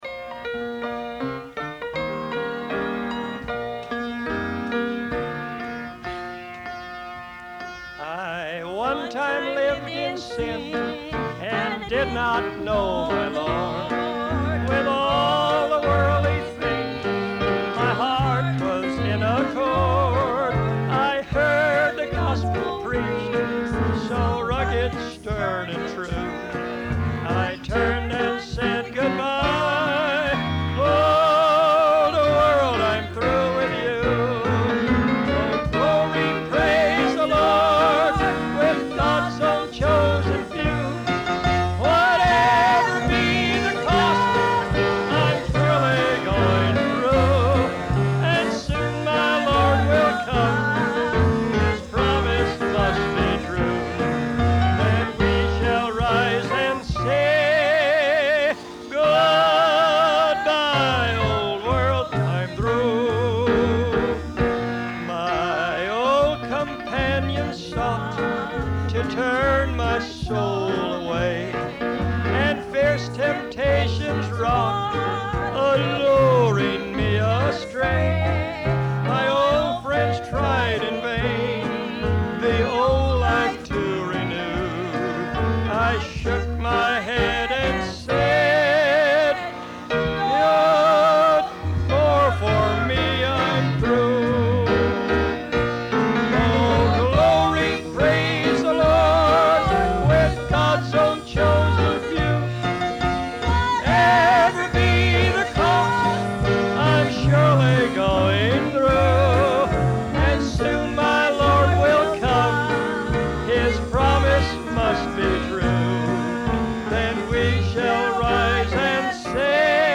They sing old melodies that are dear to our hearts.
beautiful tenor voice
beautiful vocal harmony
The date and location of this amateur recorded session is unclear. What is certain, is that this was not an attempt to be professional, but rather a preserving of memories.
is playing the piano